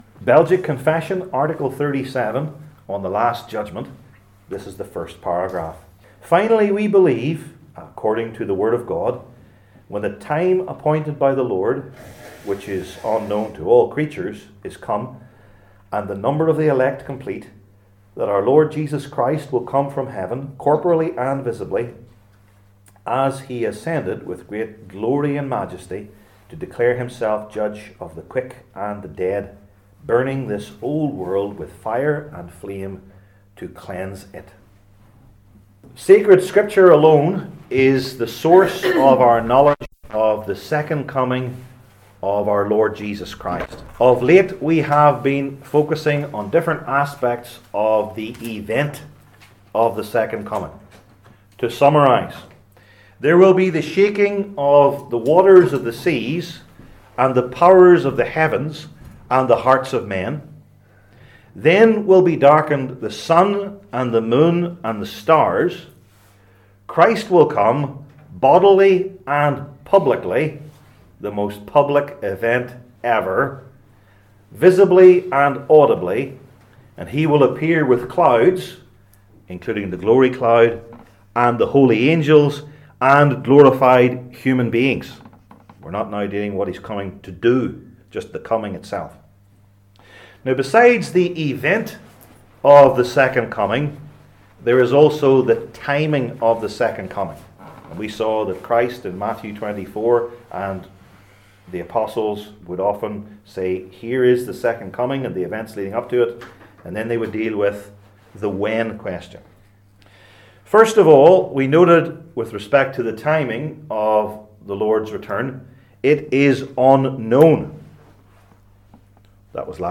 Passage: I Thessalonians 5:1-16 Service Type: Belgic Confession Classes